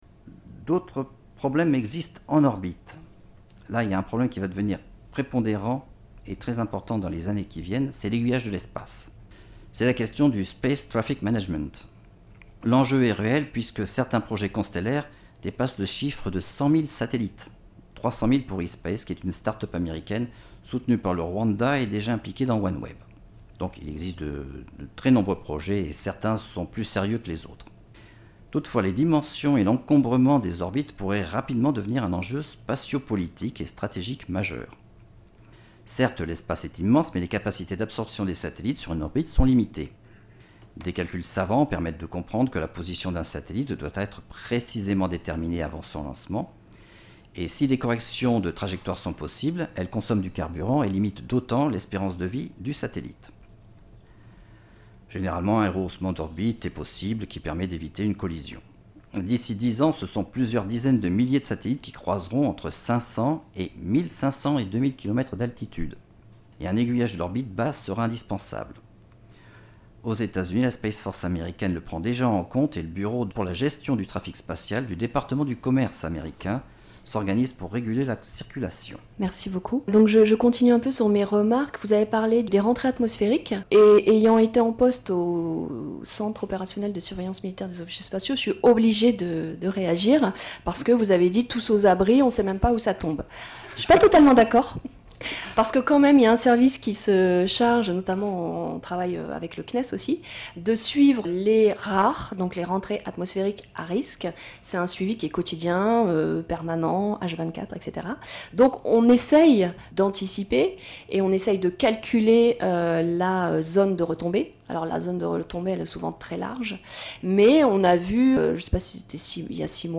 Une conférence en 8 épisodes audio :
La conférence vous est proposée en replay sous la forme d'une série audio en 8 épisodes.
Audio conférence CDEM IRSEM constellations satellitaires_3.mp3